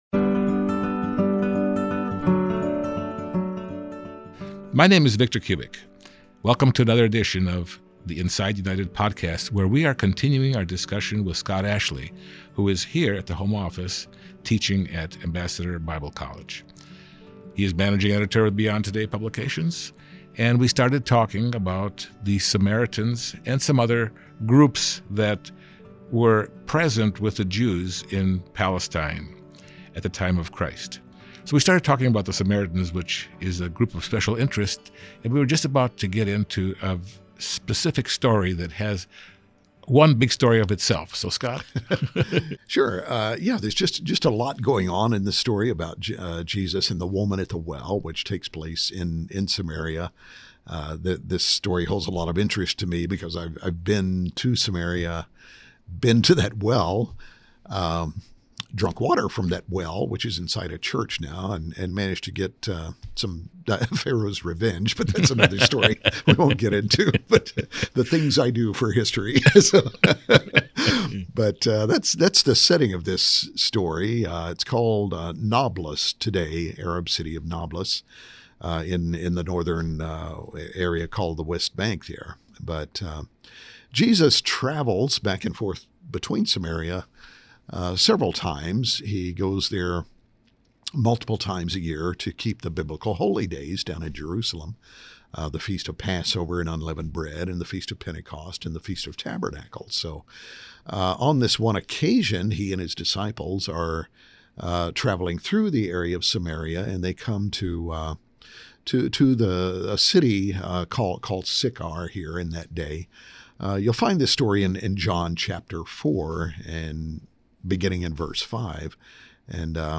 In this second part of the conversation about the Samaritans